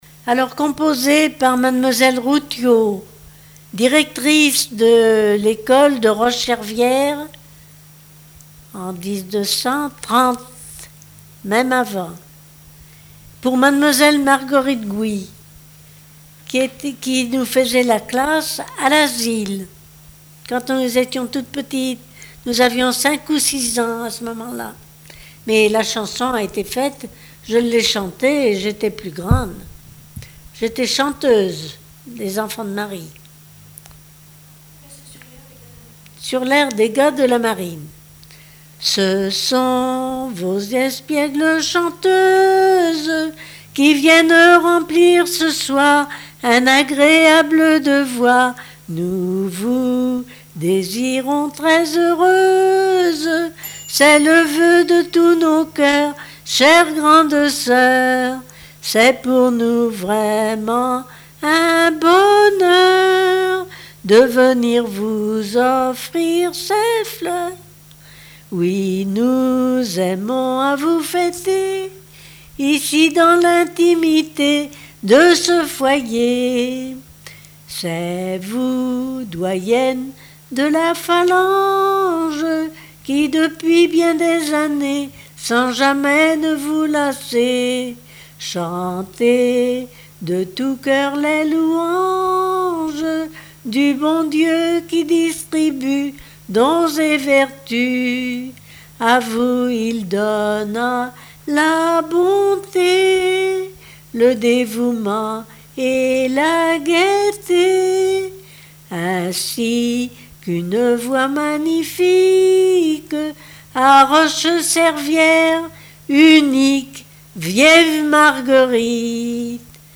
Répertoire de chansons de variété
Pièce musicale inédite